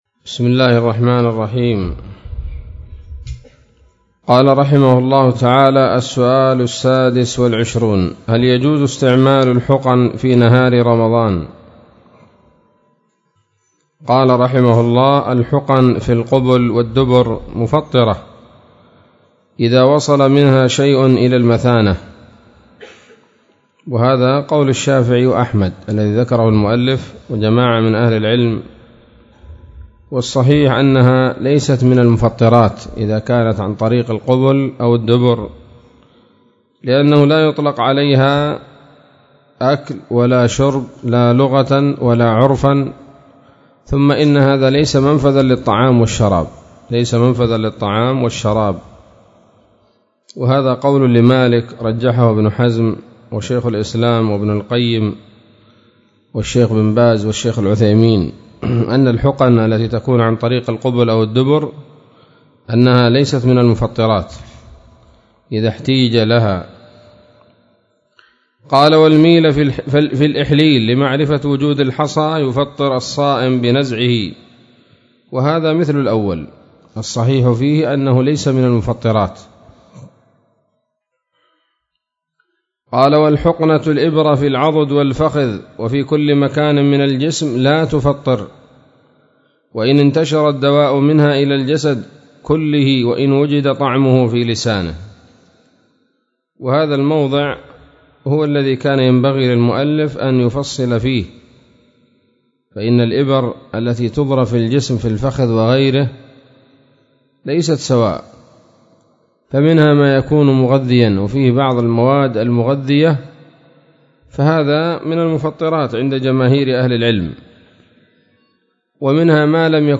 الدرس الثاني والعشرون من تحفة رمضان للعلامة البيحاني